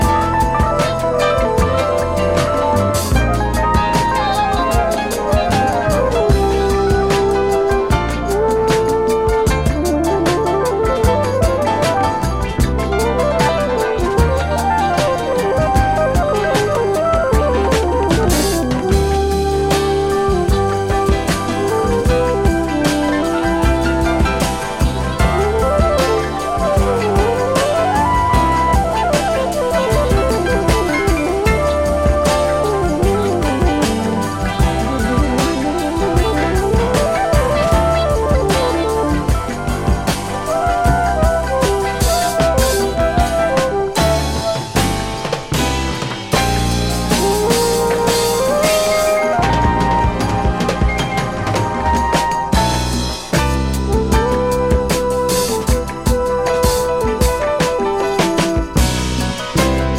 Fusion
Jazz-funk